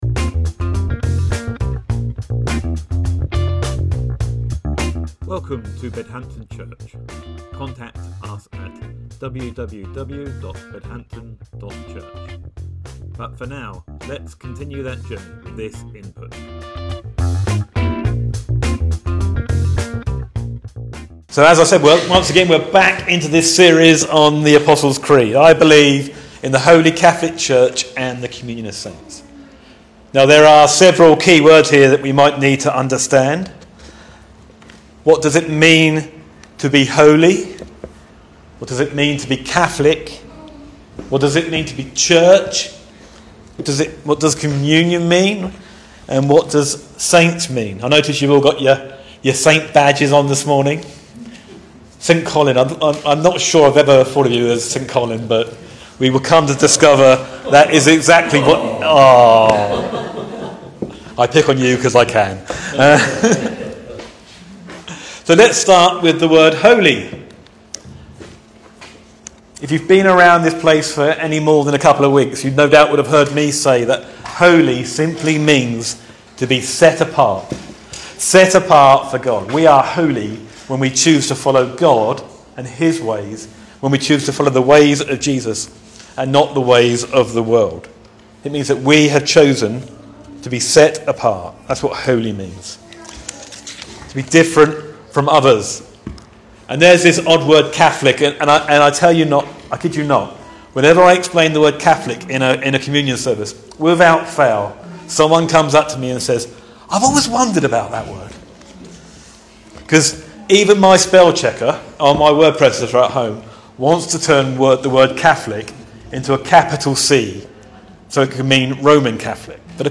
Sermon October 27th, 2024 - Creeds: The Holy catholic Church…